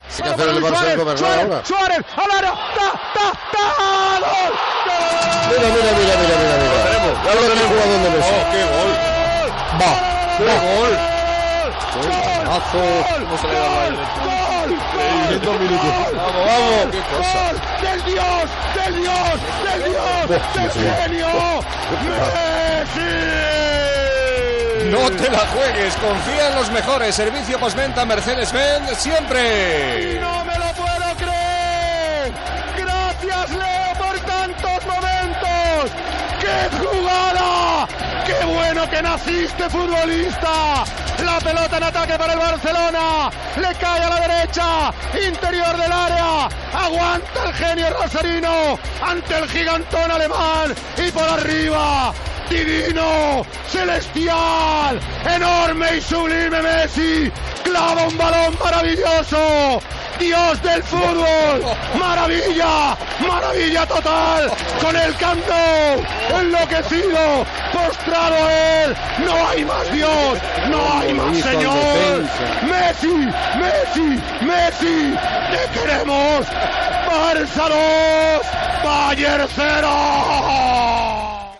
Transmissió del partit de la fase eliminatòria de la Copa d'Europa de futbol masculí entre el Futbol Club Barcelona i el Bayern München.
Narració del segon gol de Leo Messi.Reconstrucció de la jugada.